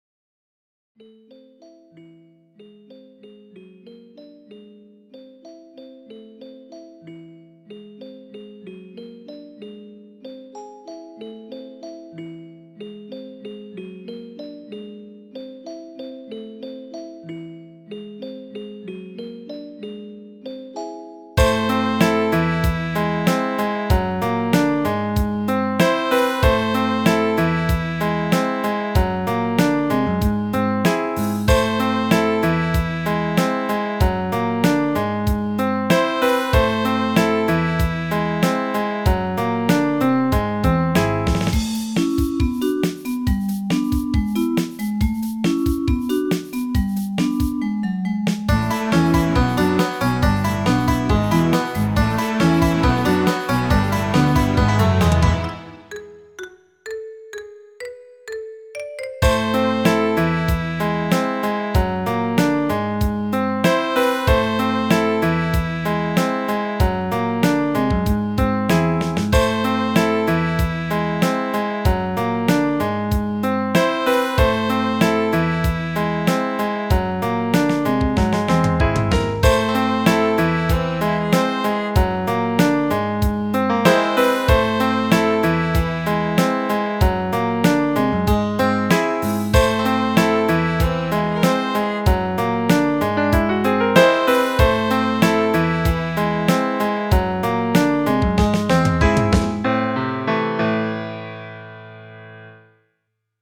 If you like augmented chords, this tune is for you.